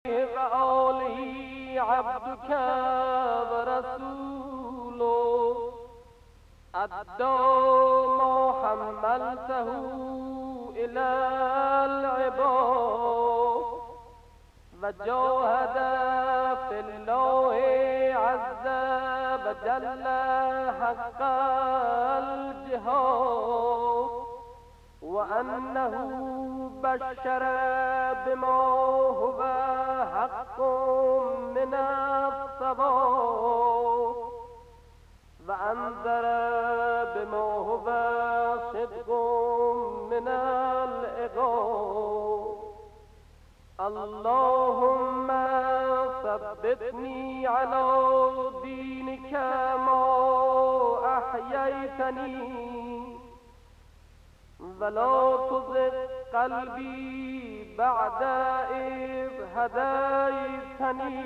longue priere radiophonique.